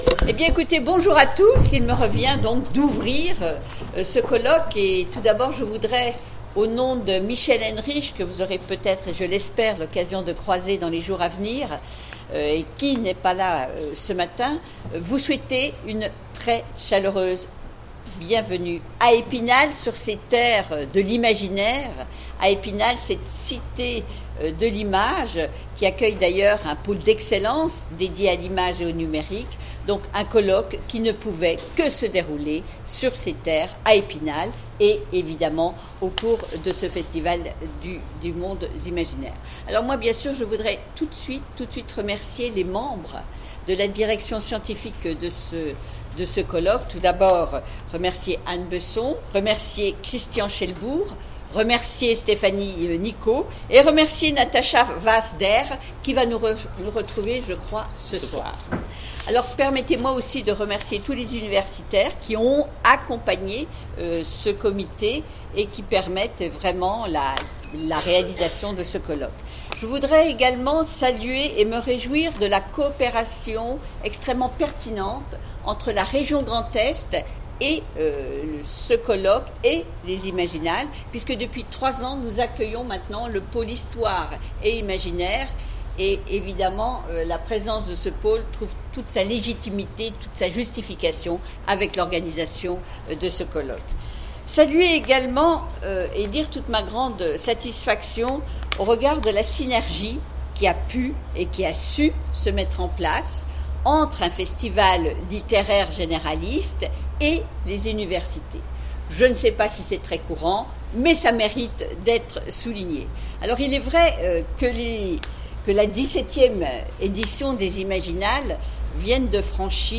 Colloque universitaire 2018 : Accueil et mot de bienvenue
Conférence